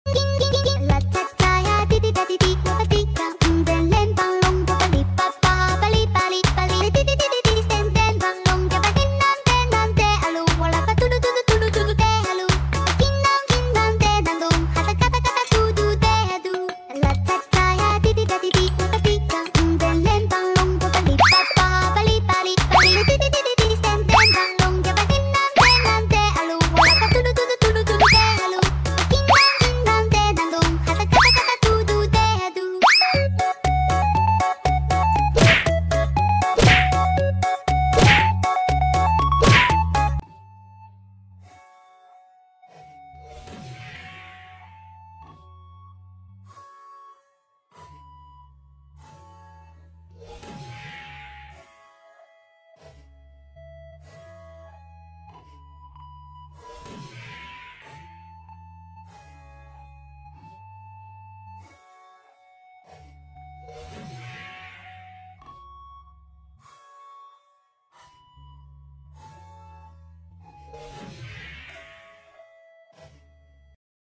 Awesome Relaxing Glass Cake Making sound effects free download
Oddly satisfying glass cake making with ASMR sand